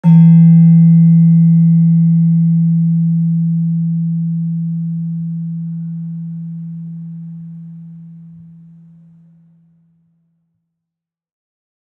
Gender-2-E2-f.wav